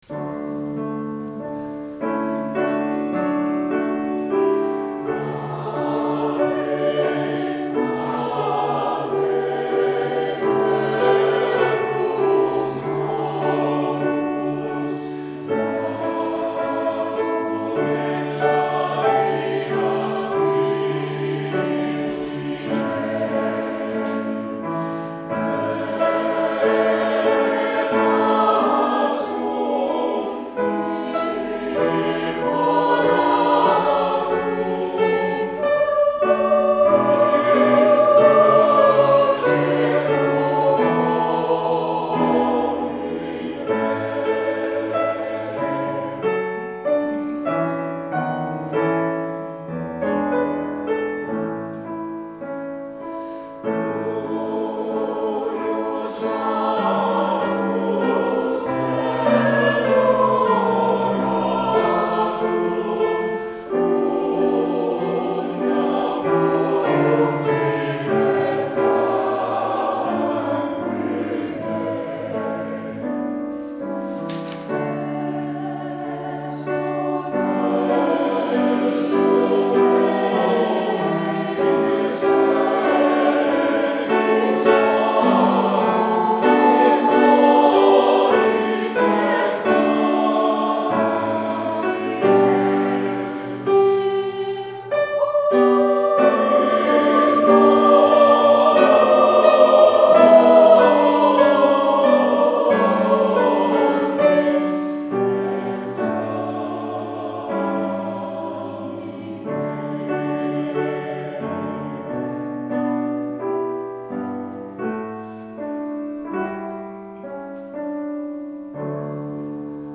Octet Plus at Summerlea sings for "Ricochet"